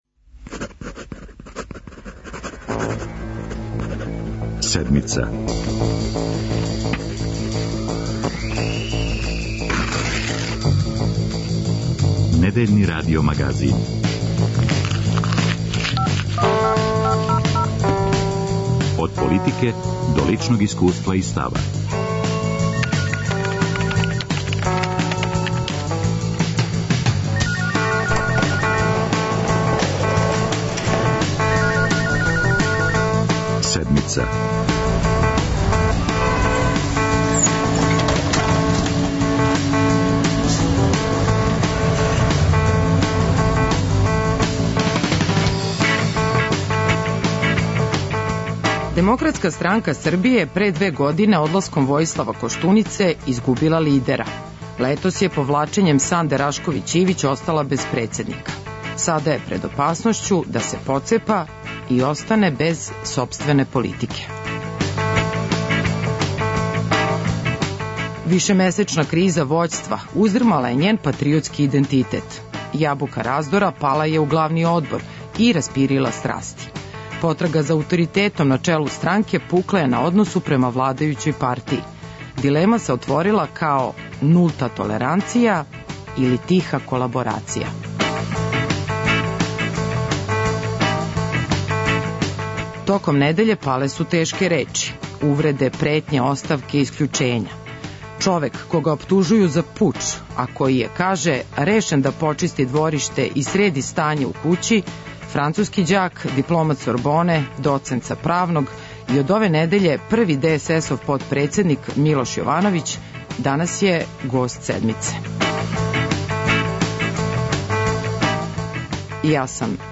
О расцепу, амбицији и циљевима, за Седмицу говори будући председник партије Милош Јовановић.